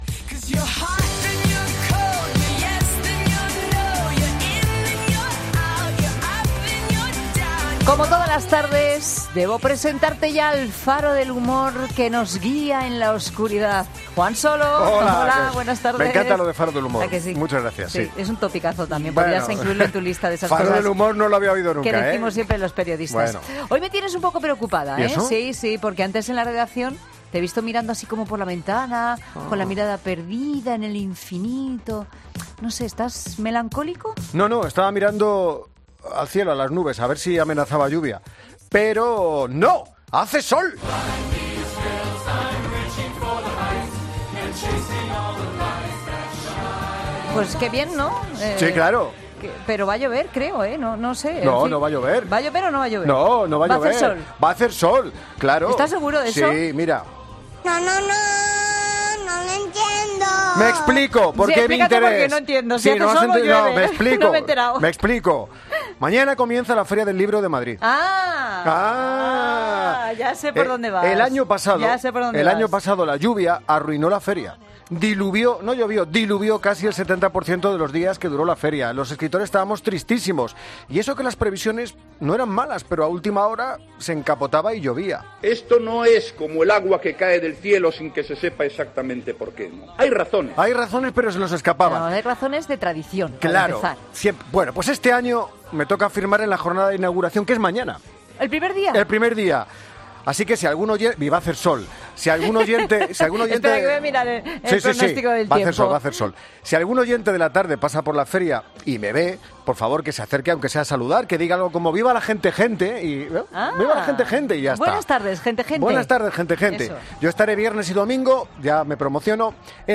Escucha ahora el humor con Juan Solo.